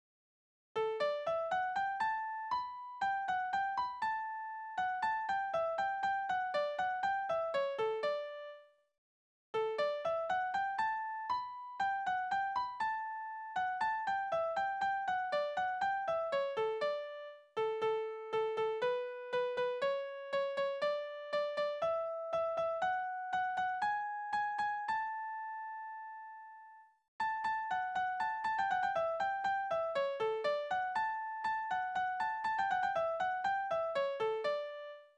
Tonart: D-Dur
Taktart: 2/4
Tonumfang: große None
Besetzung: instrumental
Anmerkung: Vortragsbezeichnungen: Polka, langsamer, Polka